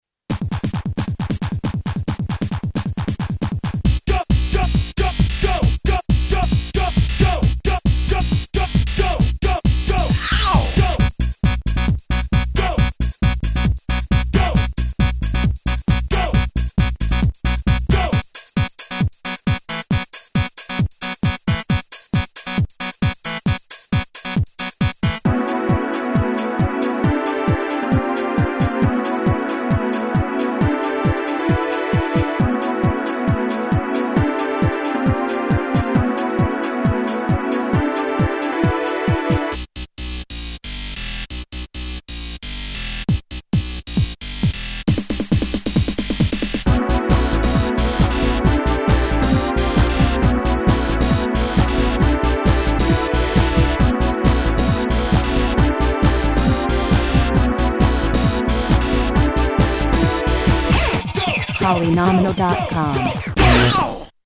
Sampling Workstation
The JS-30 is a desktop sampler dj-oriented with pads and basic internal ROM samples.
factory demo 2
- crispy sampling -limited sample parameters